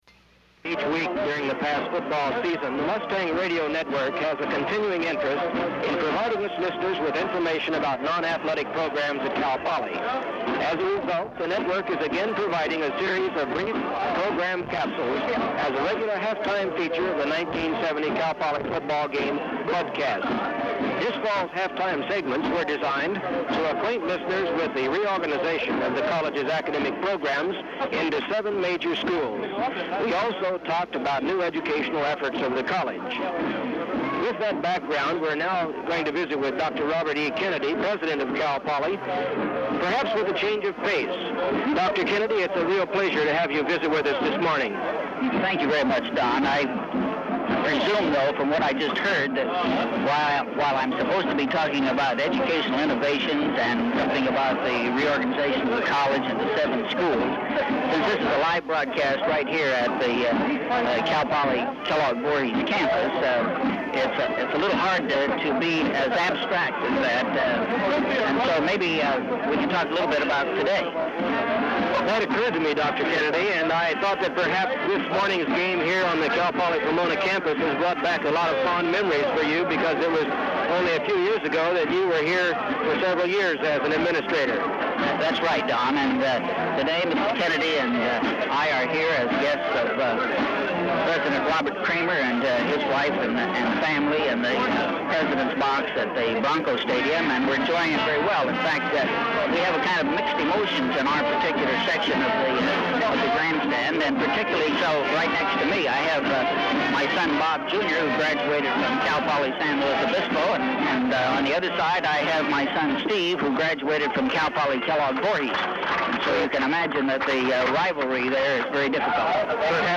Interview with Robert E. Kennedy, November 26, 1970
• Open reel audiotape